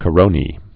(kə-rōnē)